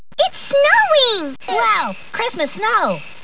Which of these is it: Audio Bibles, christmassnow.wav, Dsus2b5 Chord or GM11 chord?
christmassnow.wav